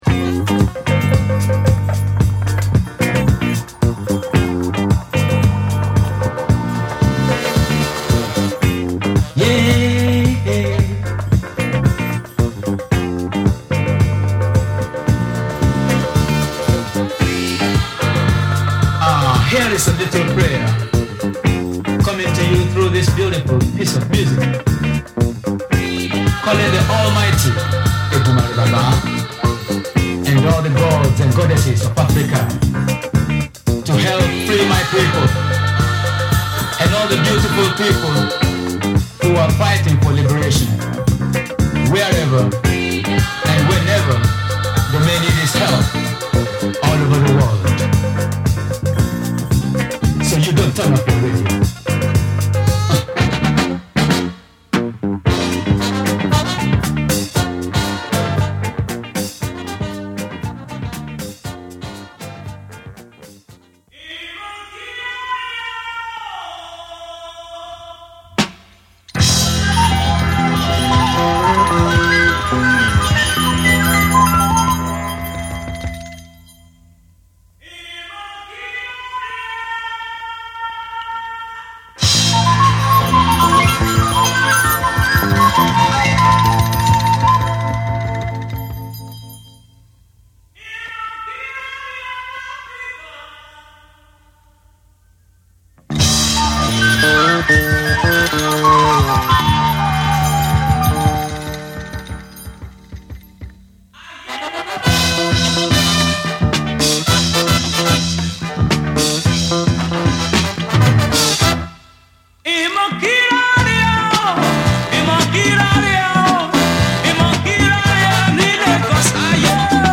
強靭なディスコ・ファンク・グルーヴとドロッとしたアフロ・ブラックネスがネットリと融合！